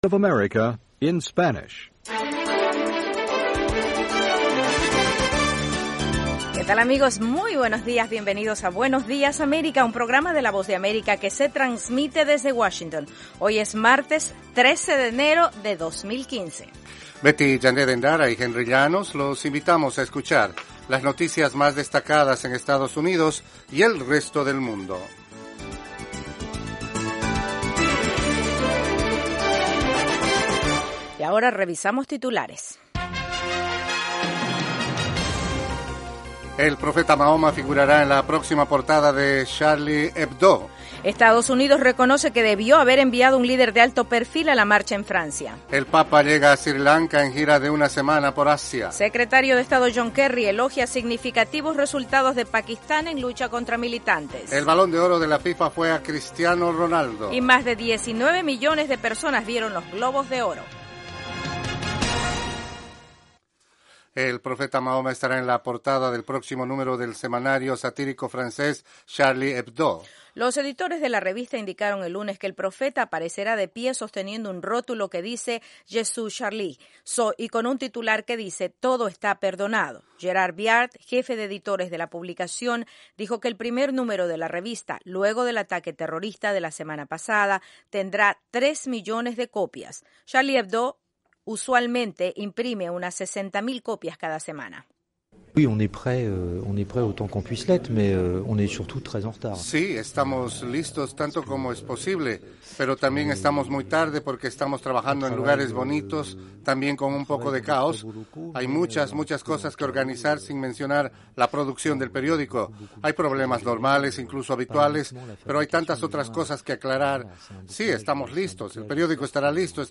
Buenos días América es un programa informativo diario de media hora dirigido a nuestra audiencia en América Latina. El programa se transmite de lunes a viernes de 8:30 a.m. a 9:00 a.m. [hora de Washington].